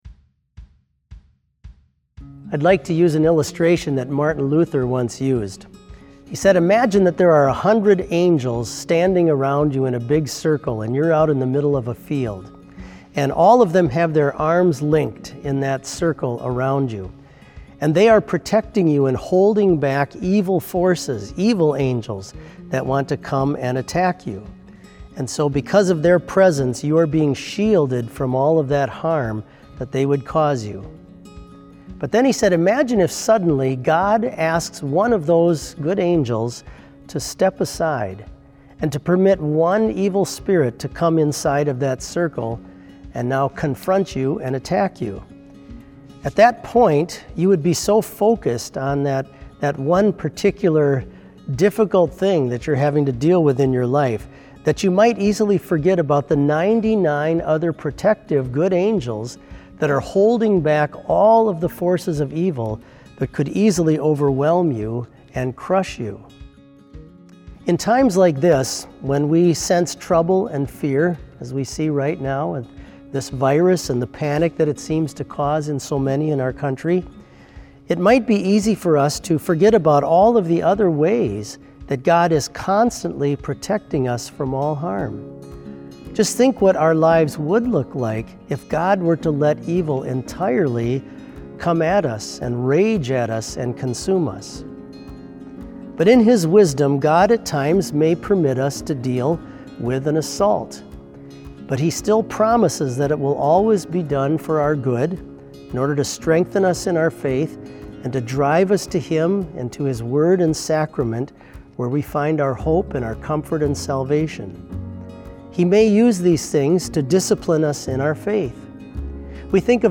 Complete service audio for BLC Devotion - March 19, 2020